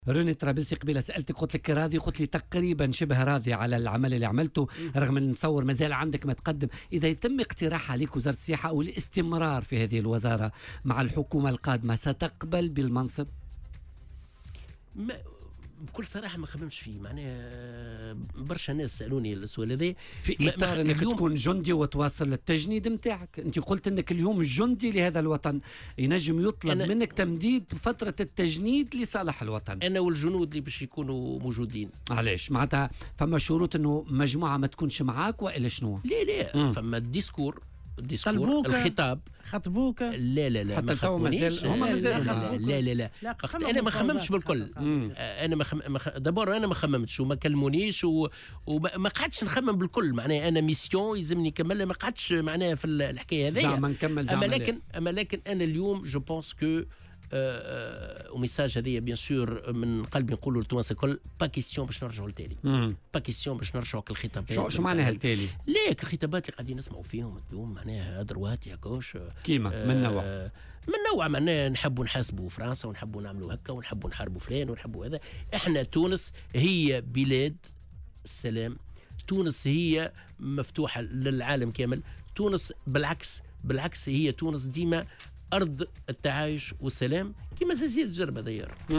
وأشار الطرابلسي، ضيف برنامج "بوليتيكا" اليوم على "الجوهرة أف أم" إلى أنه لا مجال للعودة بتونس إلى الوراء، مؤكدا أن تونس أرض التعايش والسلام وأنه يرفض كل الخطابات التي تدعو إلى الكراهية.